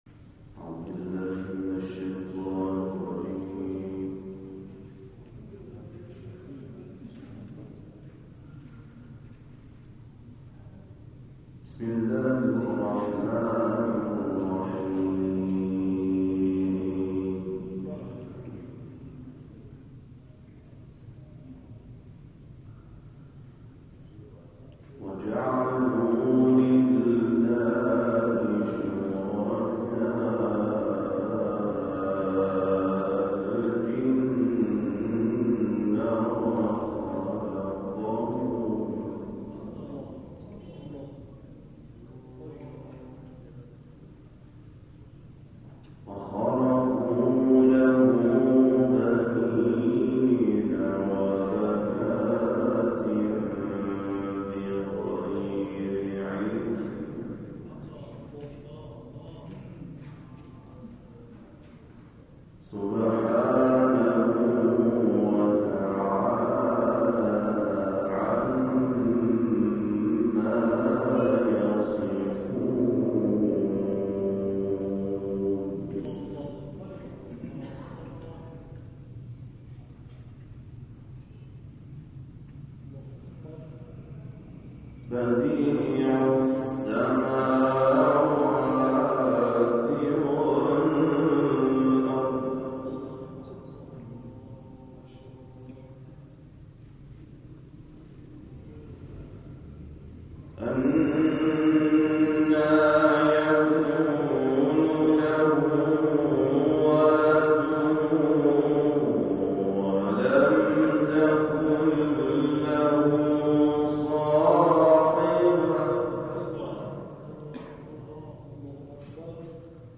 افتتاحیه جلسه آموزش قرآن با رویکرد تدریس در زمینه‌های تجوید و صوت و لحن
در مسجد بینایی واقع در خیابان تختی تهران آغاز شد.